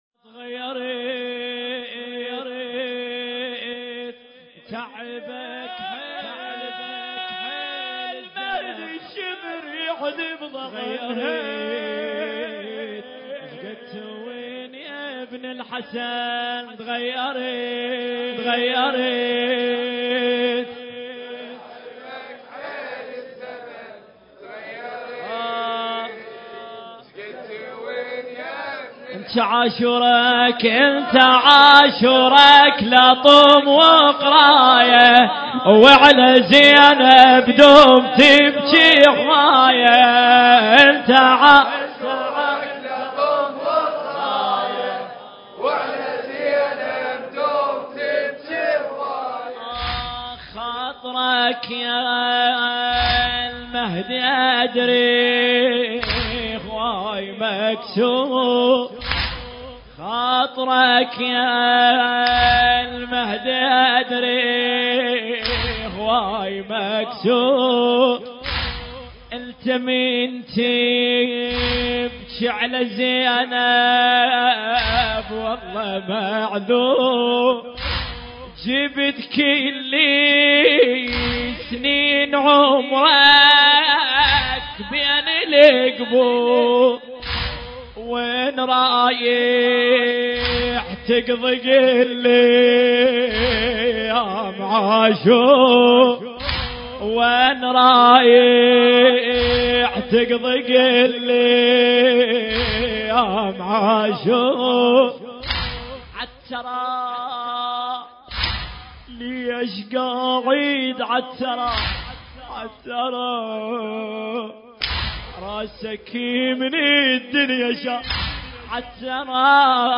الحجم: 2.25 MB الشاعر: تحسين الخفاجي التاريخ: 1440 للهجرة المكان: موكب عقيلة بني هاشم (عليها السلام) – الرميثة